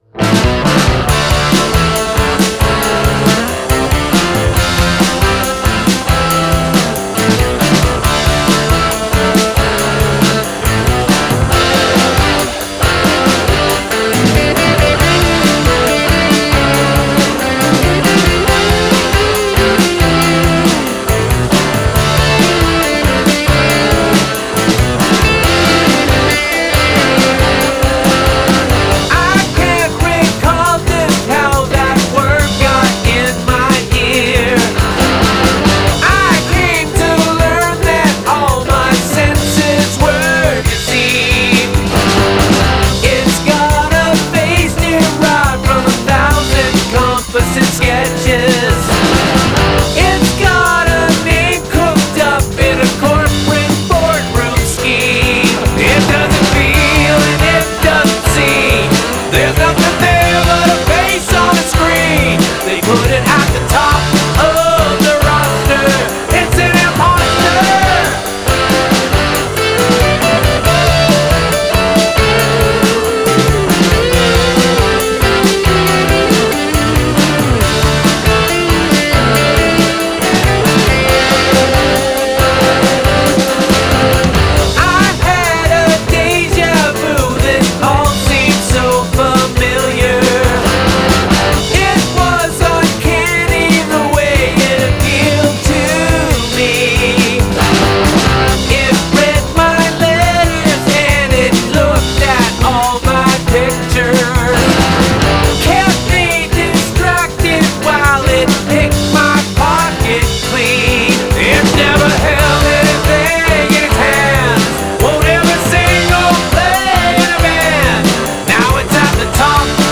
An original retro-rawk work-in-progress about generative AI of all things. Done by myself in Ardour 9.2. I don’t know if it’s too silly to work on further or whether I’ll move on to other things but thought I’d post anyway in case the sentiment resonates.
Especially during the spoken sequence. lol
Not having paid to much attention to the lyrics for first time listening (considering English is not my first language anyway) I find the musical ideas very interesting and compelling.
Very well played, sung, and arranged, I particularly like the lead and solo guitar.
The drums like you mixed them utterly fit with the song’s atmo.